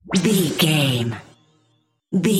Sound Effects
funny
magical
mystical